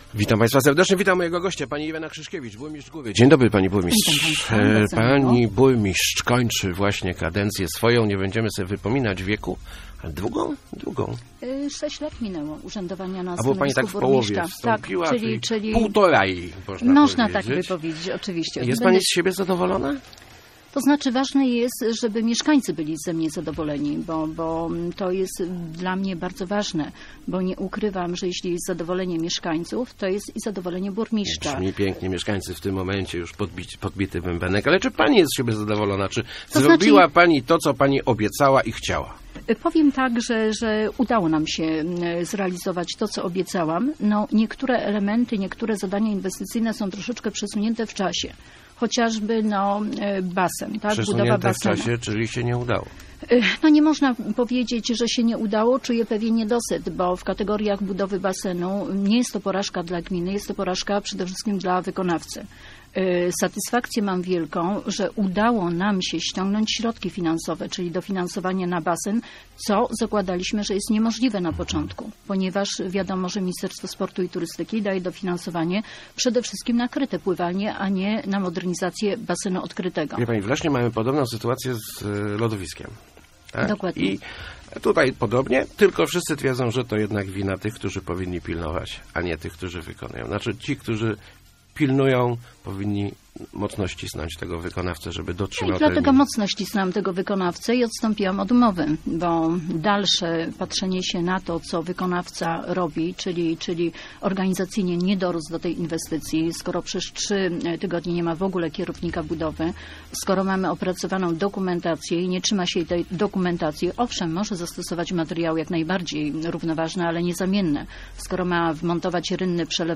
W przyszłym roku przystąpimy do budowy obwodnicy - zapewniała w Rozmowach Elki Irena Krzyszkiewicz, burmistrz Góry.